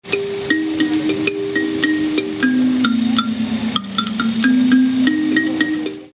Instrumento aborigen australiano
instrumento musical
percusión